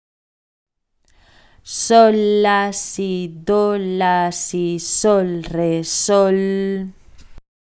Neste caso, a velocidade ou pulso varía pero as figuras teñen a mesma duración.
O compás de 6/8 irá máis lento de pulso porque ten máis corcheas, mentres que ao chegar ao 2/4 teredes que ir un pouco máis áxiles porque son menos figuras.